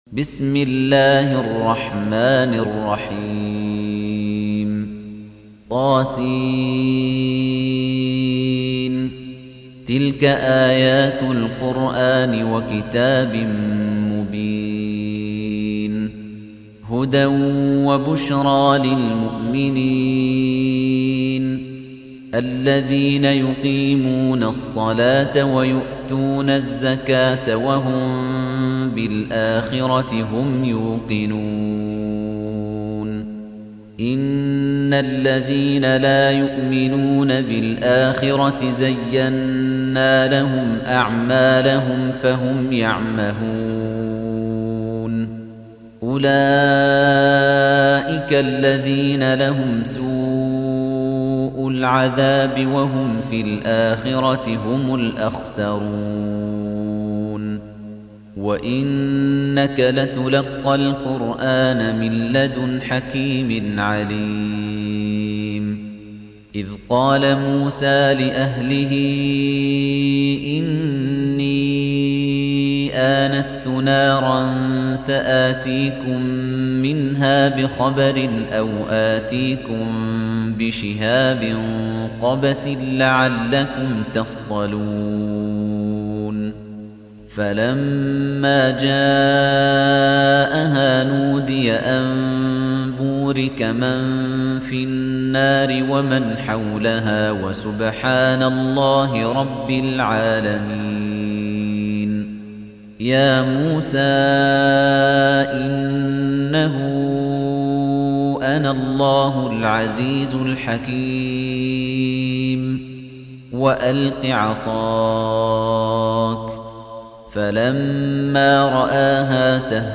تحميل : 27. سورة النمل / القارئ خليفة الطنيجي / القرآن الكريم / موقع يا حسين